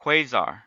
Ääntäminen
US : IPA : /ˈkweɪ.zɑɹ/